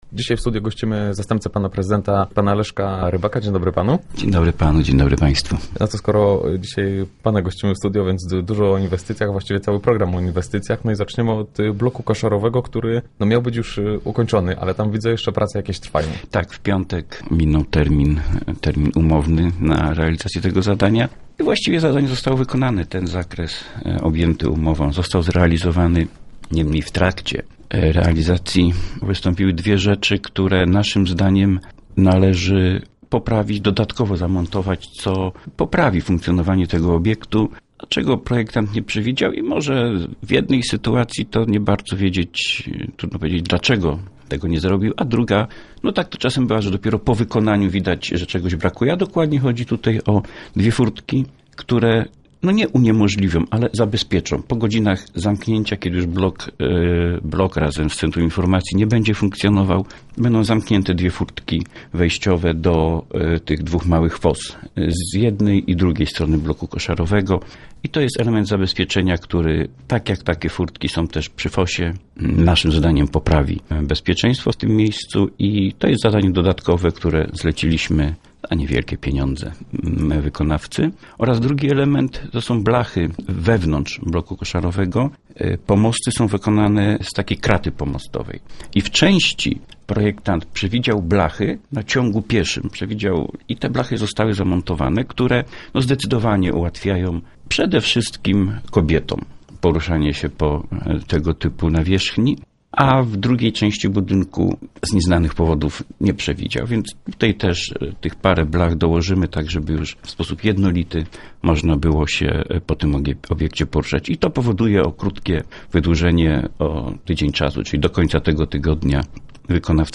O szczegó�ach opowiada Leszek Rybak, zast�pca prezydenta.